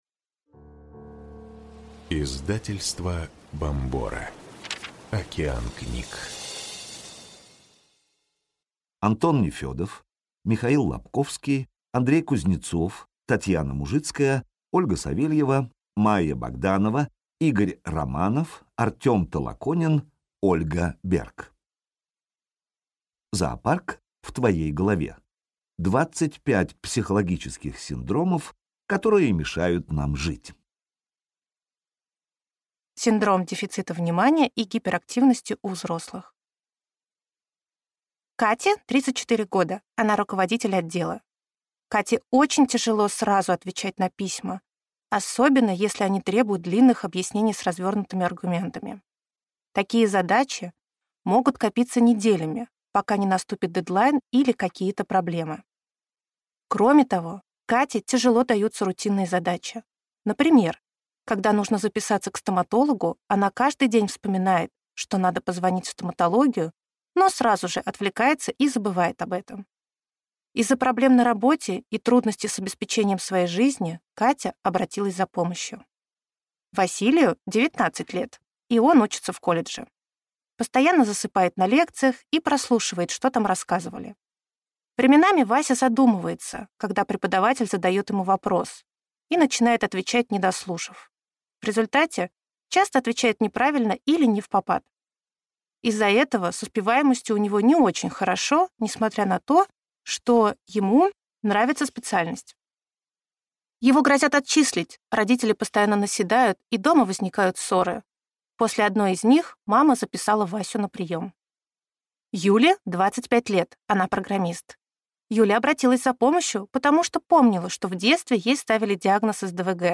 Аудиокнига Зоопарк в твоей голове. 25 психологических синдромов, которые мешают нам жить | Библиотека аудиокниг